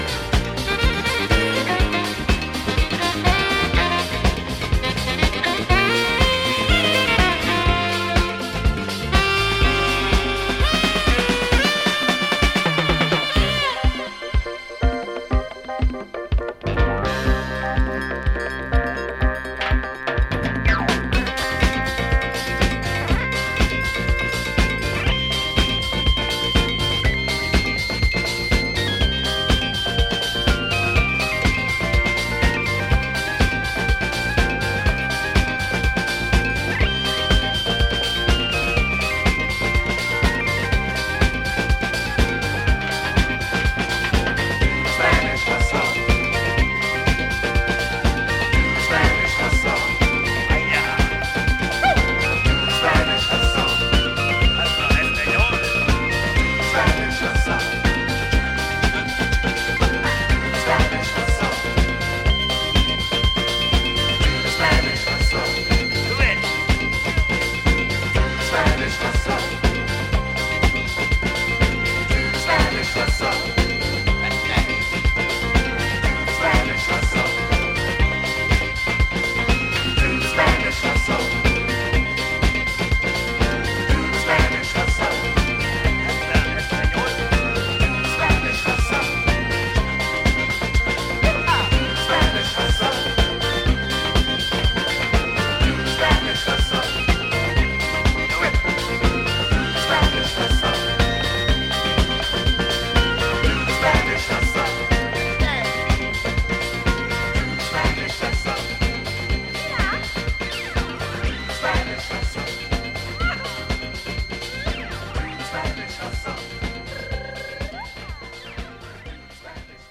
ジャンル(スタイル) DISCO / SOUL / FUNK / RE-EDIT